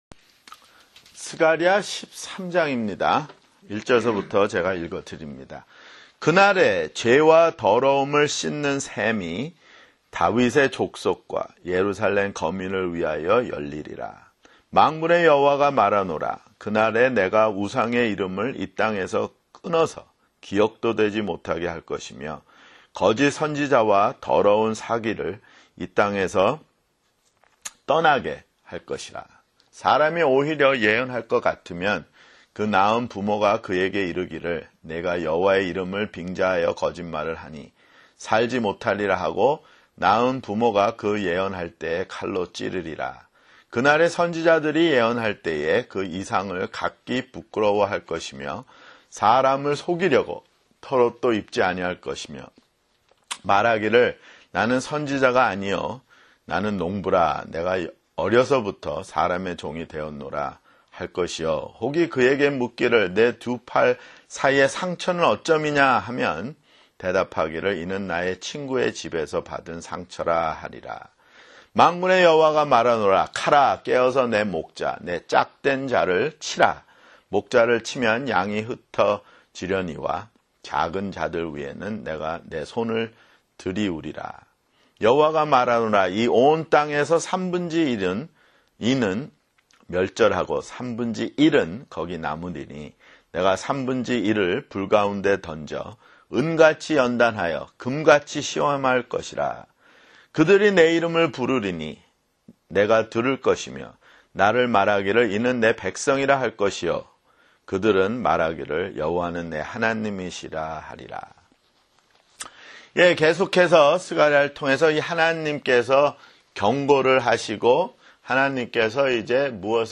[성경공부] 스가랴 (48)